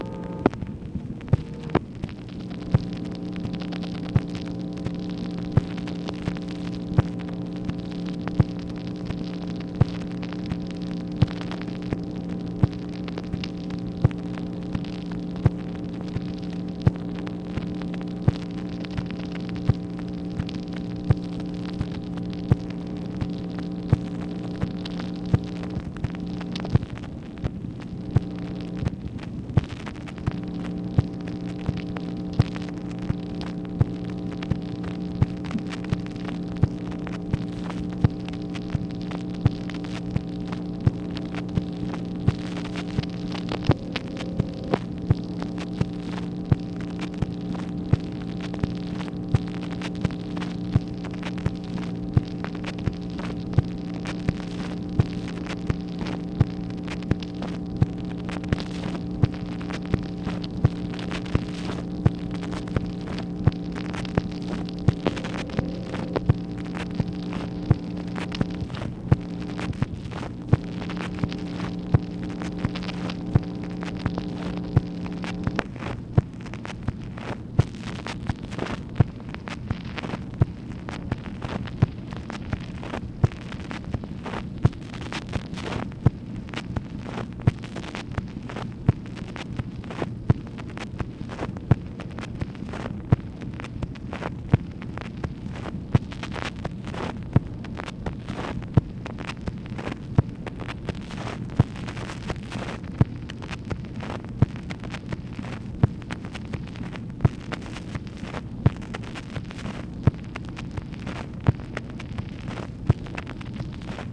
MACHINE NOISE, May 25, 1965
Secret White House Tapes | Lyndon B. Johnson Presidency